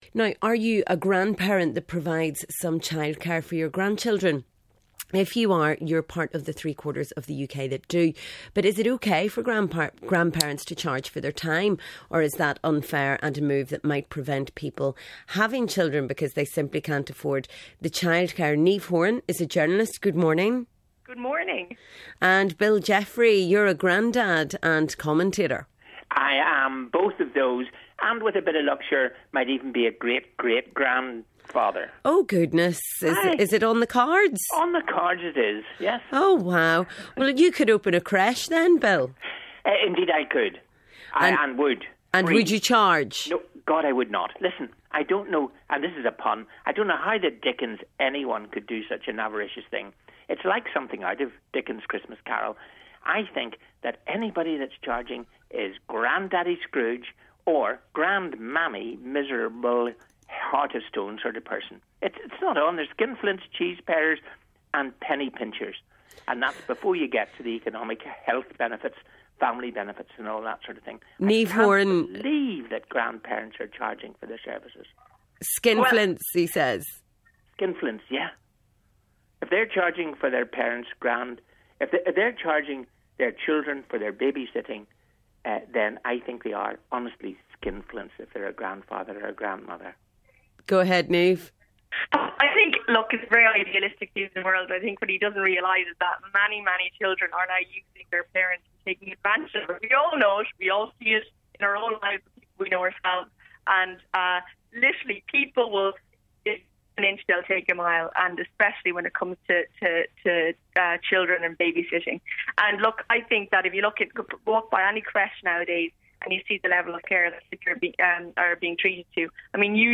commentator and grandfather